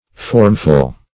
Formful \Form"ful\
formful.mp3